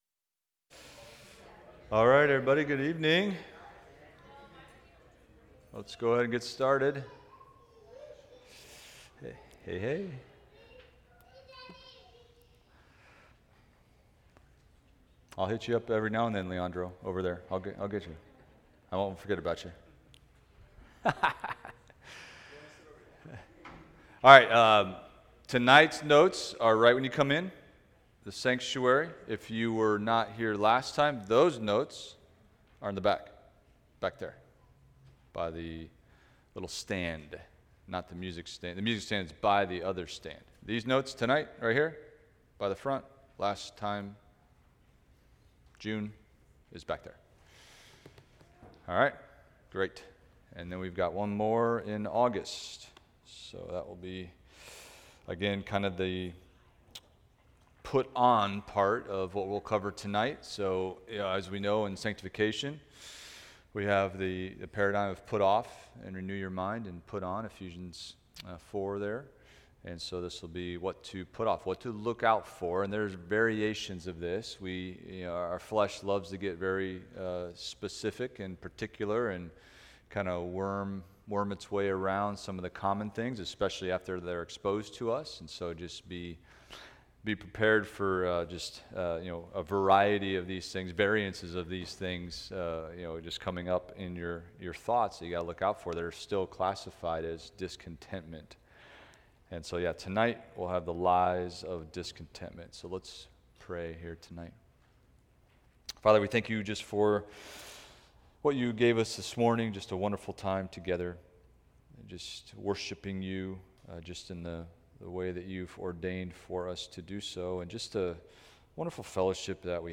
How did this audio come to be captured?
Summer Sunday Evening series on Contentment.